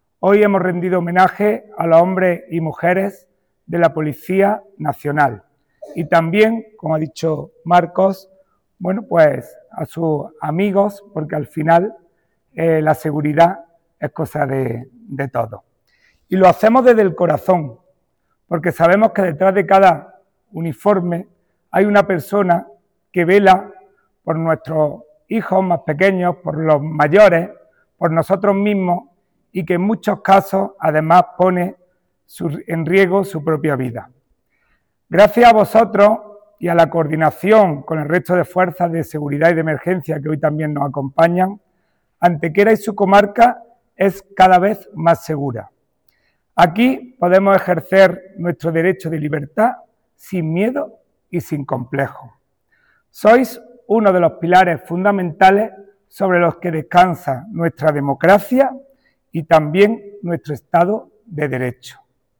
El primer teniente de Alcalde y vicepresidente de la Diputación de Málaga, Juan Rosas, fue el encargado de intervenir en nombre del Ayuntamiento durante el acto conmemorativo de la festividad de los Santos Ángeles Custodios, patronos de la Policía Nacional.
Cortes de voz